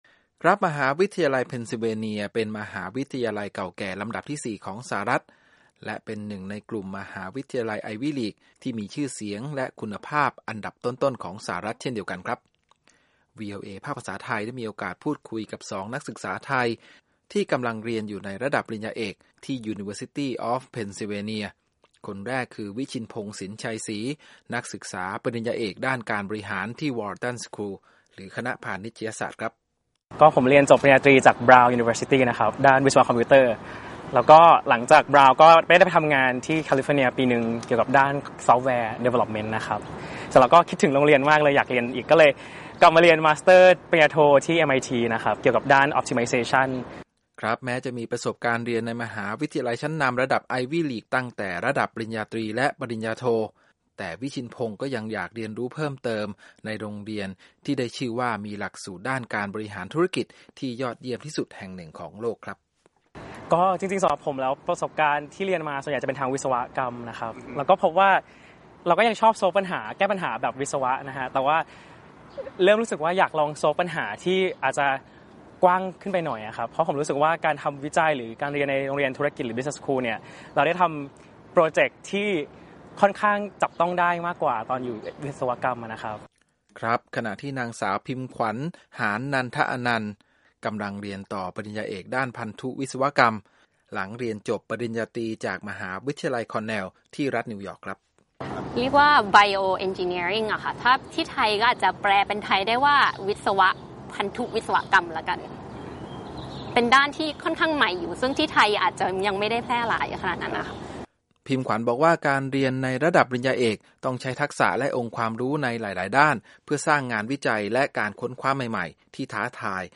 วีโอเอ ไทย พาไปคุยกับ 2 นักศึกษาระดับปริญญาเอกจากมหาวิทยาลัยเพนซิลเวเนีย หรือ University of Pennsylvania ในนครฟิลาเดลเฟียหนึ่งในสถาบันอุดมศึกษาเก่าแก่และเป็นสมาชิกมหาวิทยาลัย ไอวีลีค ของสหรัฐฯ
นั่งสนทนากับวีโอเอ ไทย ใต้ต้นไม้ บริเวณลานกว้างด้านหน้าอาคารคอลเลจ ฮอลล์ ใจกลางมหาวิทยาลัยเพนซิลเวเนีย